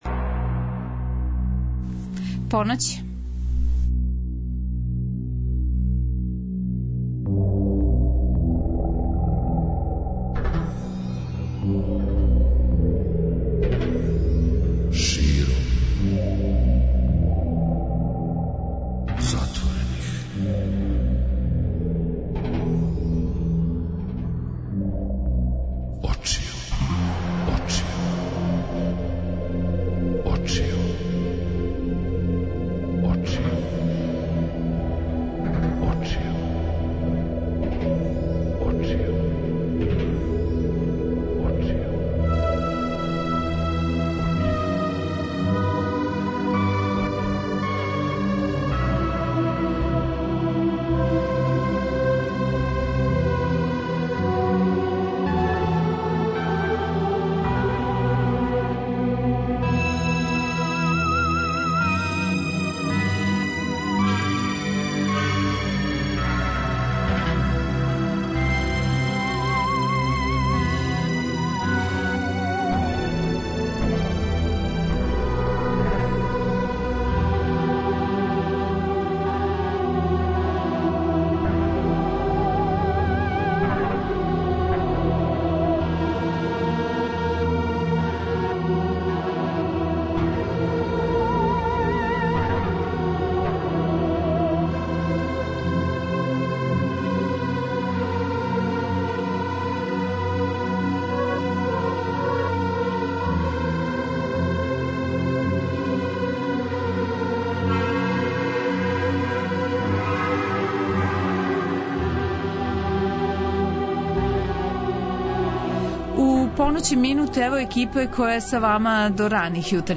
Гости: Жика и Драги Јелић, Ју група